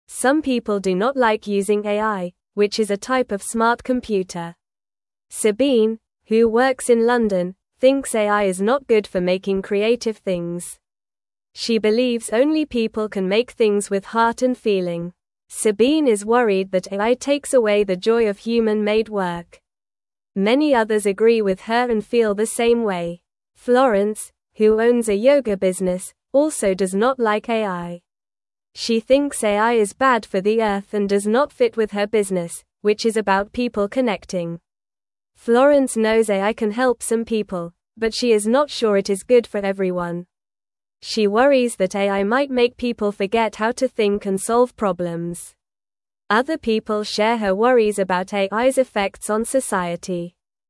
Normal
English-Newsroom-Beginner-NORMAL-Reading-People-Worry-About-AI-and-Creativity-and-Connection.mp3